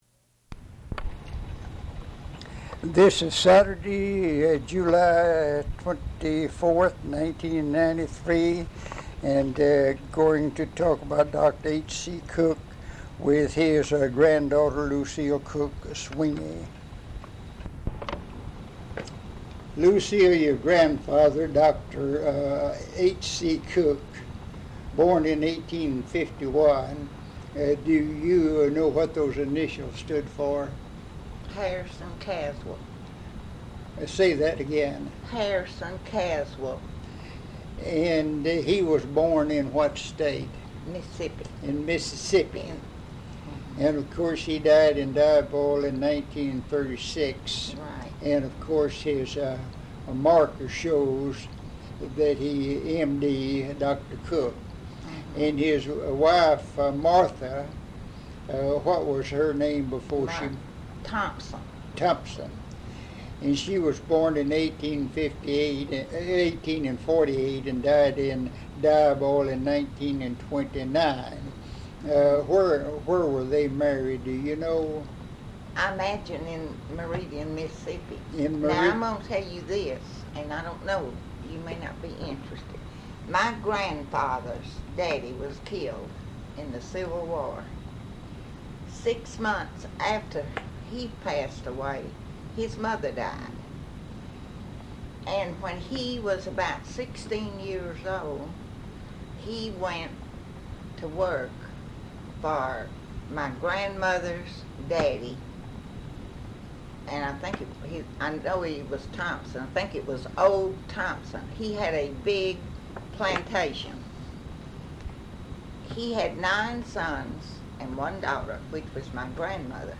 Interview 139a